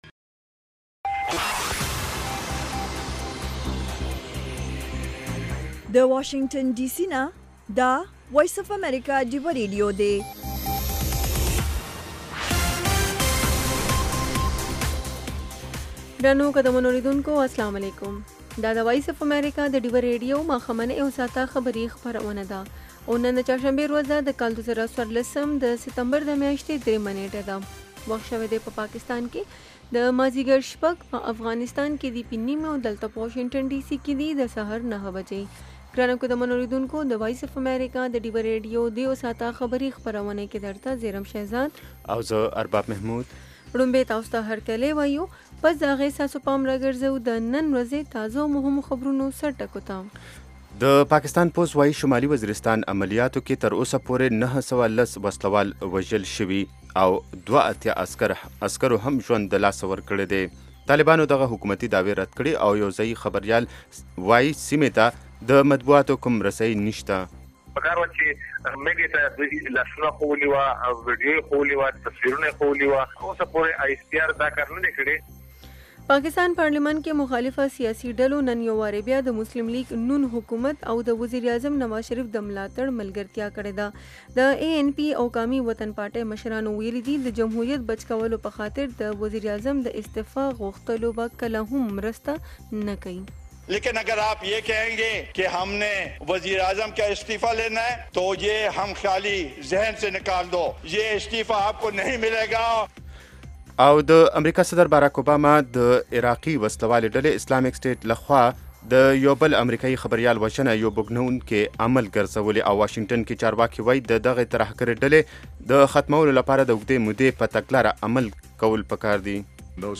خبرونه - 1300
د وی او اې ډيوه راډيو ماښامنۍ خبرونه چالان کړئ اؤ د ورځې د مهمو تازه خبرونو سرليکونه واورئ.